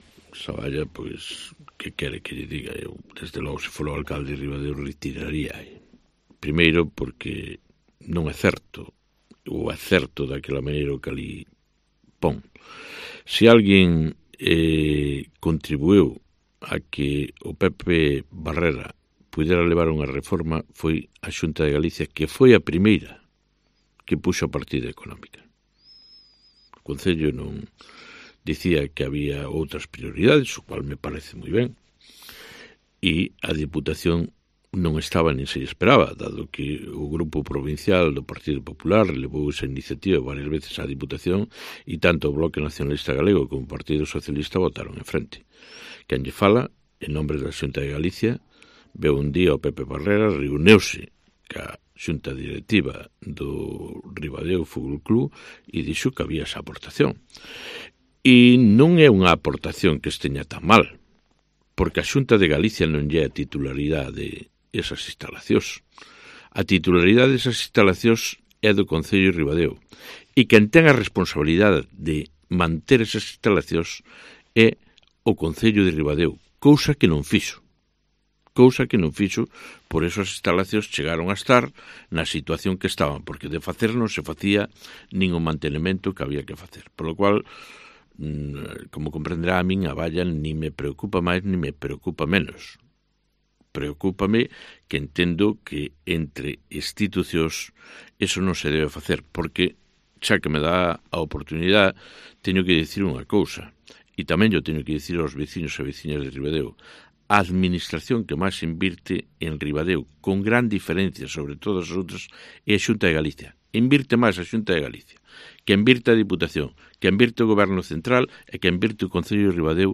DECLARACIONES del delegado territorial sobre la valla del Pepe Barrera